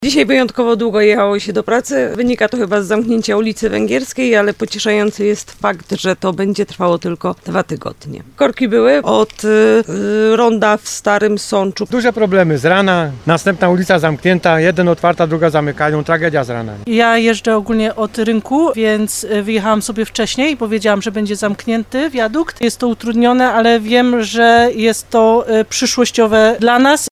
Zagrodzony został przejazd w okolicach wiaduktu kolejowego – sprawdziliśmy co na to kierowcy?
3sonda_Wegierska.mp3